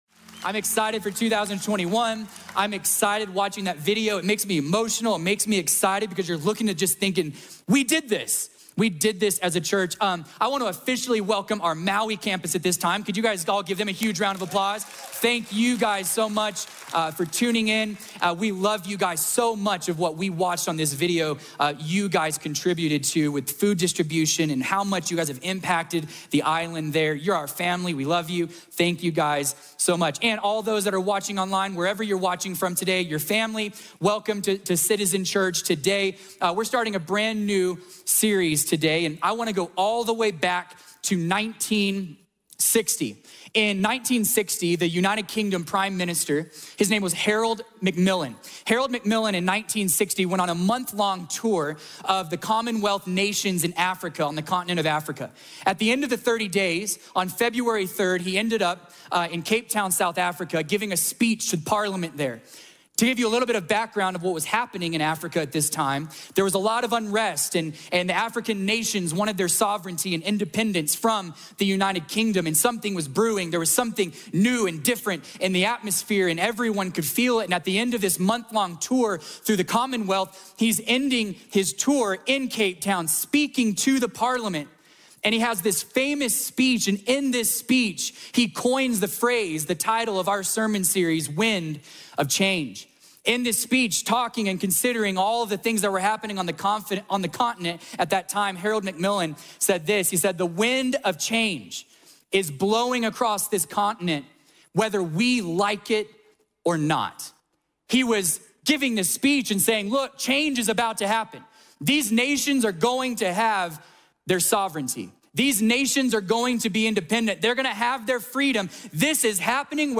Subscribe to the Citizen Church Podcast and automatically receive our weekly sermons.